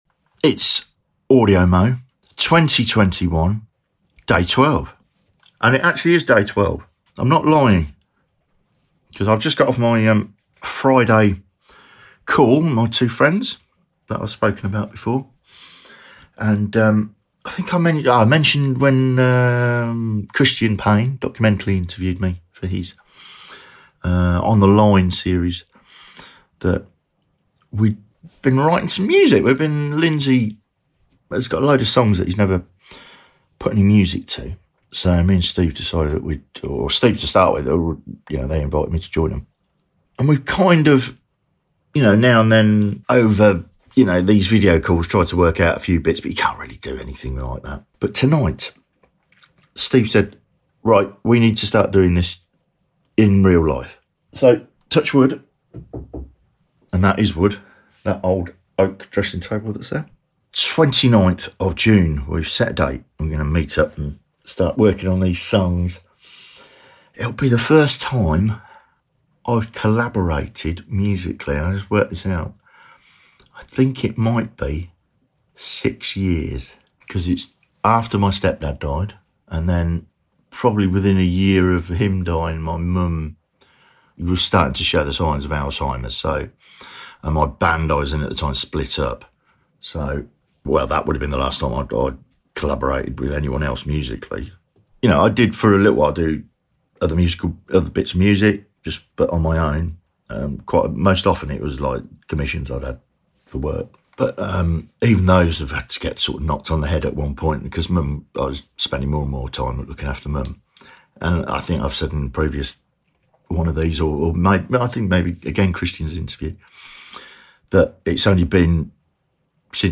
Making music again ...